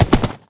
gallop3.ogg